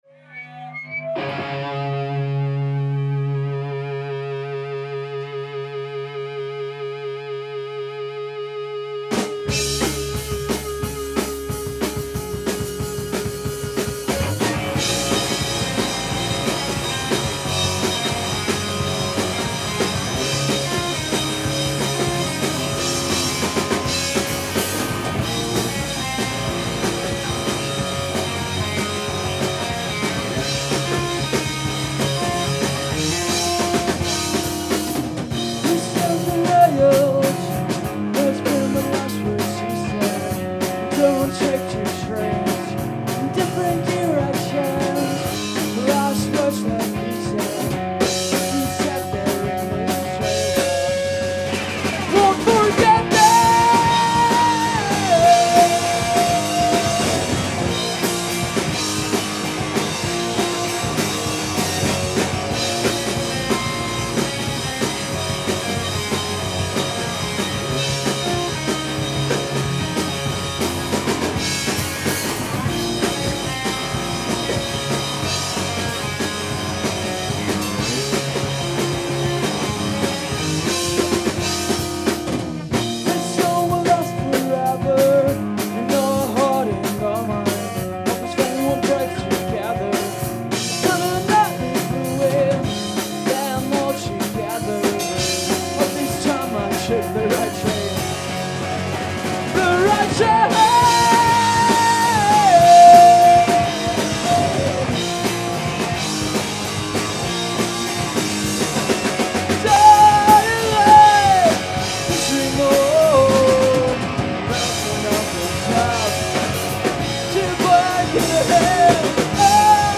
Gitarre
Bass
Drum